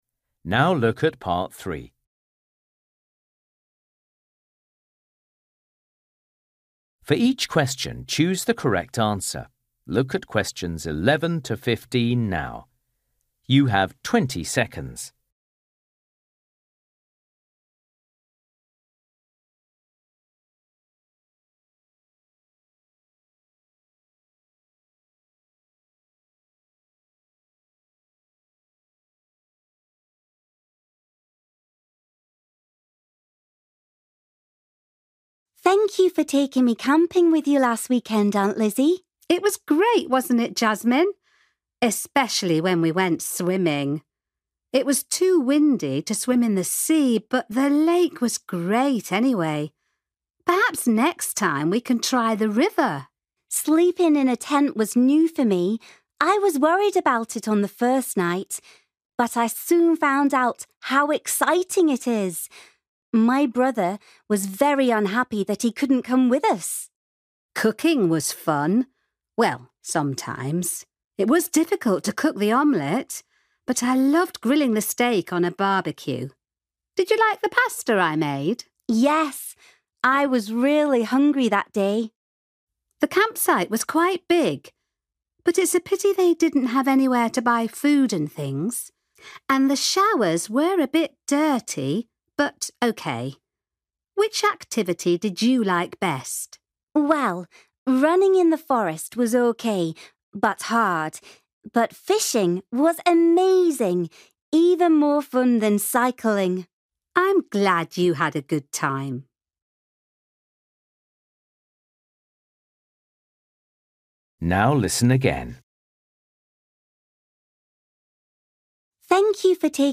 You will hear Jasmine talking to her aunt about a camping trip.